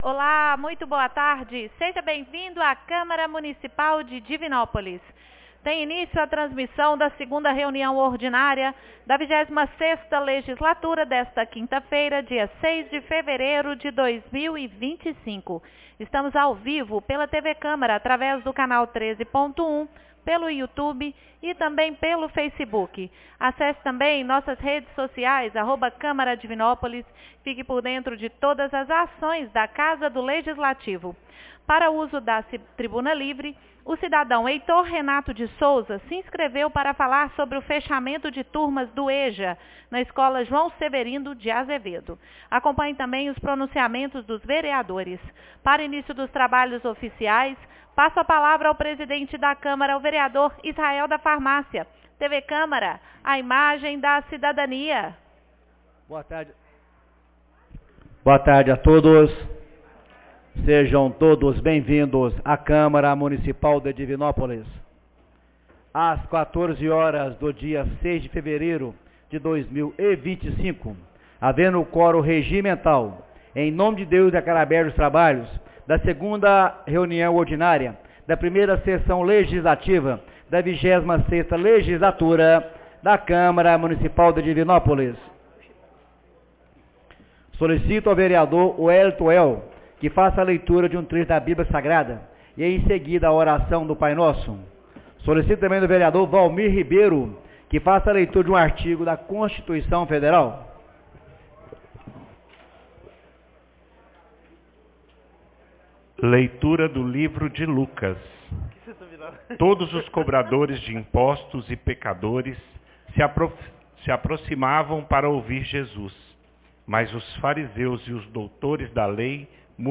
2ª Reunião Ordinária 06 de fevereiro de 2025